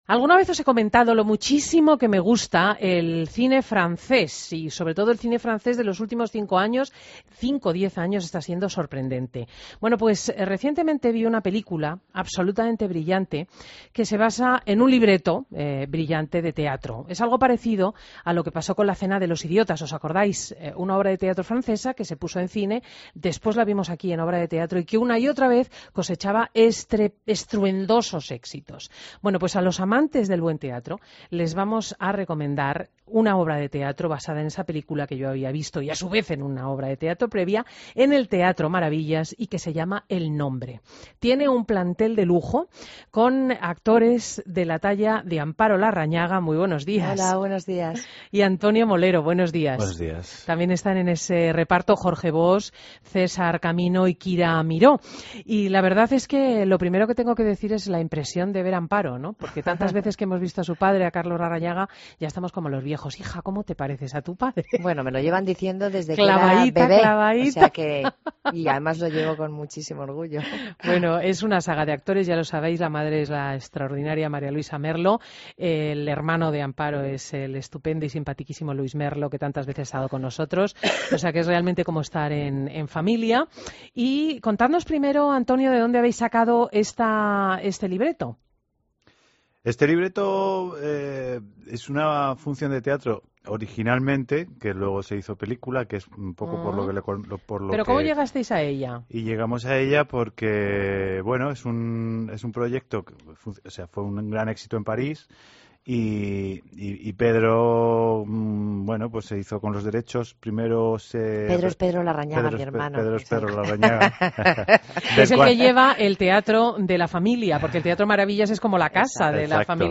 Entrevista a Amparo Larrañaga en Fin de Semana